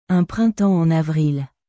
Un printemps en avrilアン プランタン アォン アヴリル